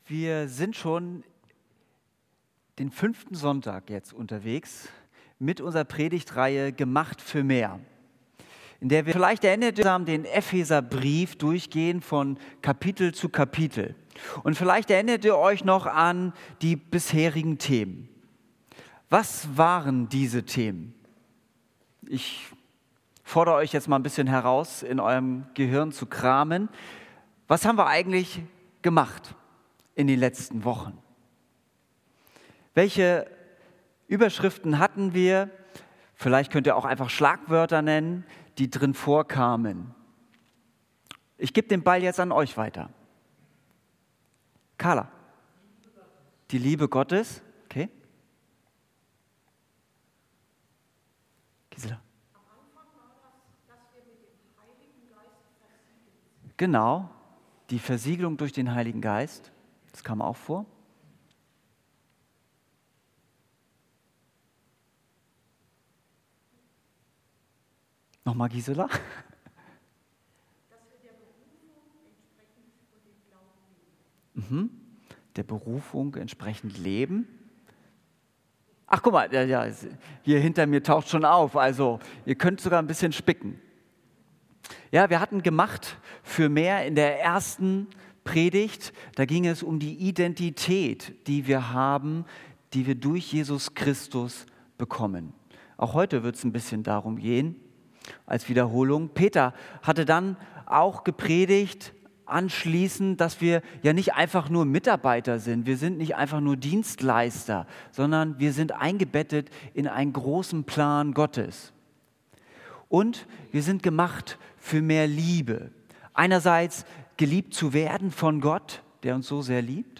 Gottesdienst
Predigt